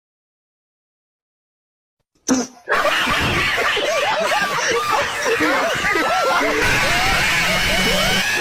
Laugh Loud Tho Bouton sonore